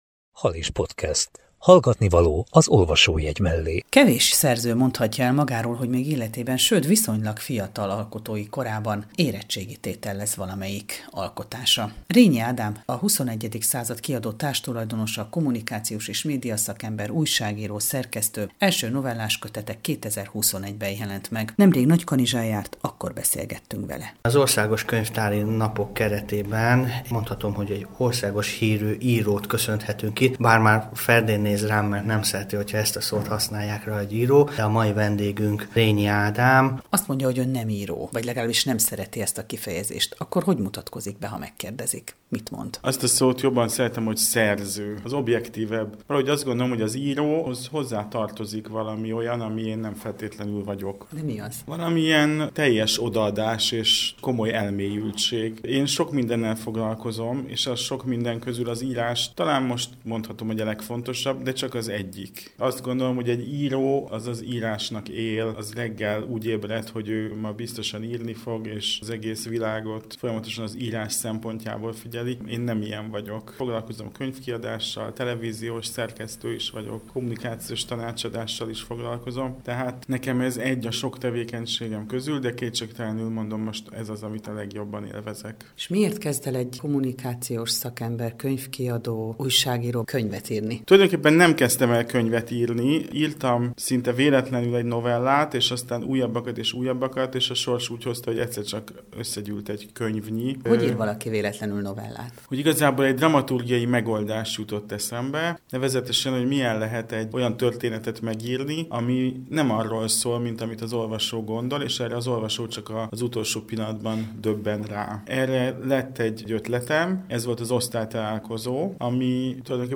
Halis Podcast 71 - Hétköznapi emberek rejtett titkai - beszélgetés